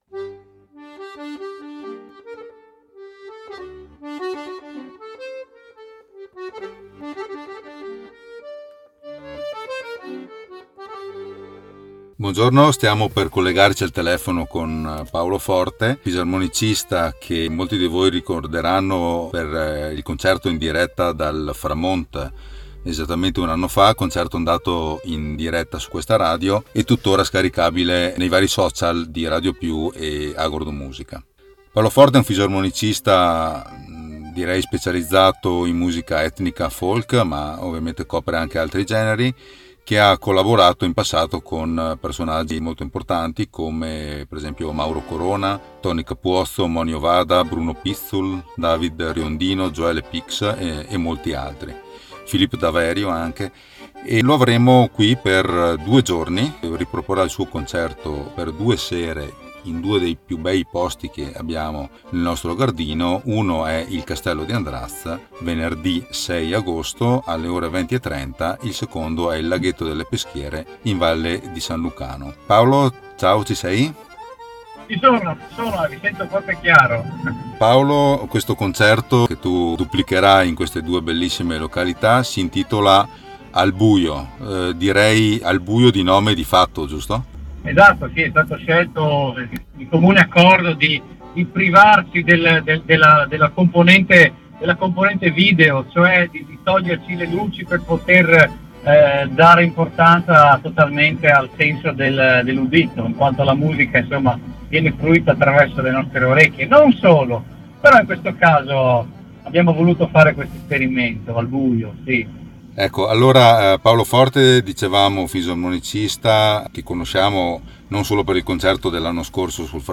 I CONCERTI AL CASTELLO DI ANDRAZ (VENERDI) E SAN LUCANO PESCHIERE (SABATO), L’INTERVISTA